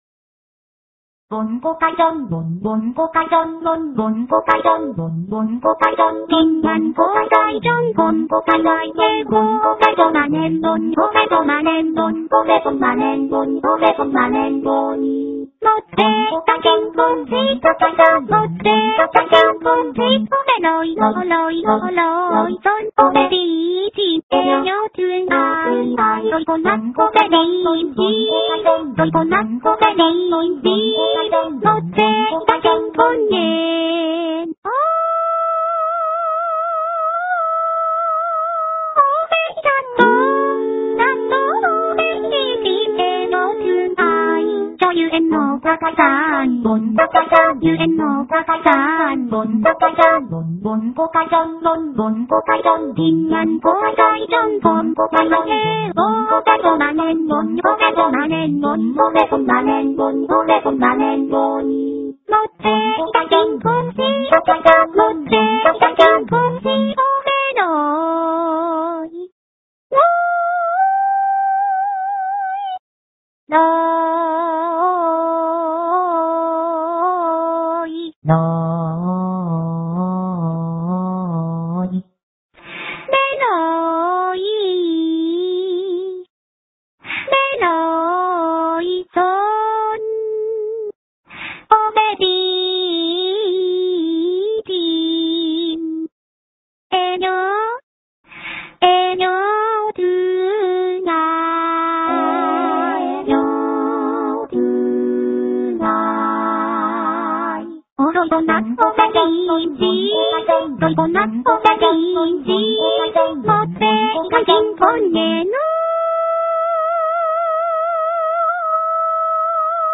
Une Japonaise chante« Trống cơm »